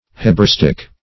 Search Result for " hebraistic" : The Collaborative International Dictionary of English v.0.48: Hebraistic \He`bra*is"tic\, a. Pertaining to, or resembling, the Hebrew language or idiom.